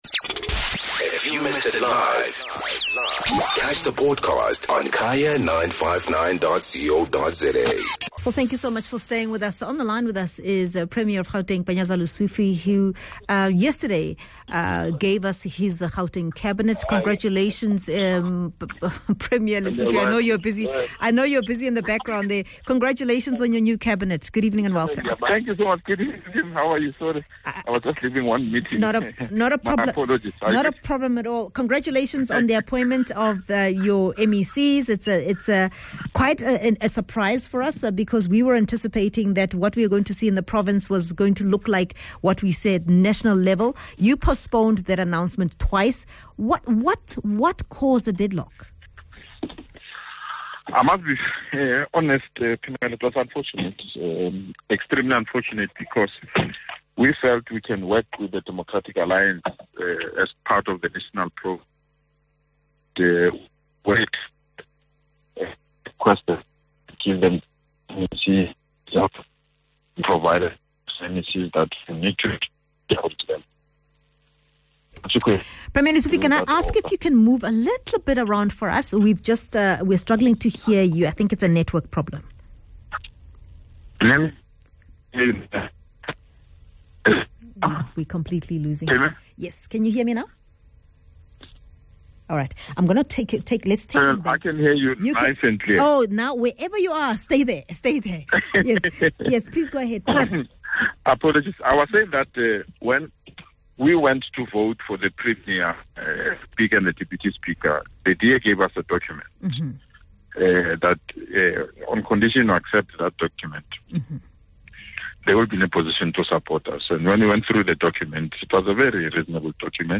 Guest: Panyaza Lesufi - Gauteng Premier